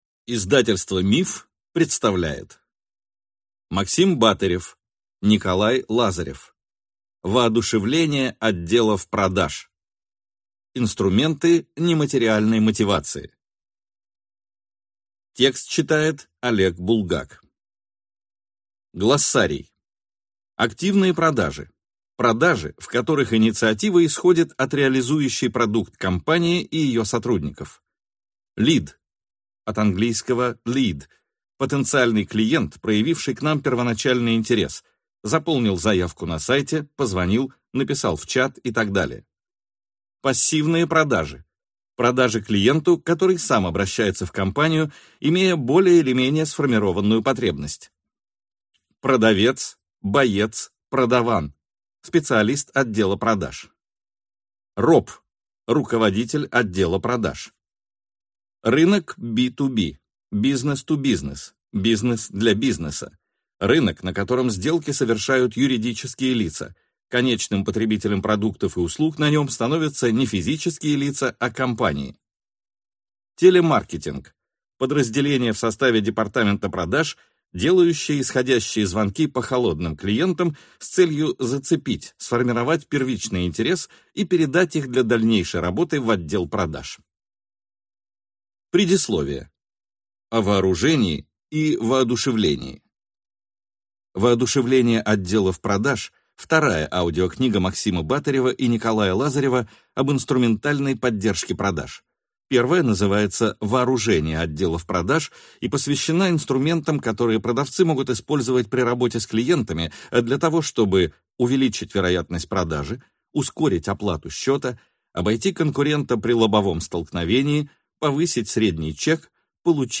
Аудиокнига Воодушевление отделов продаж. Инструменты нематериальной мотивации | Библиотека аудиокниг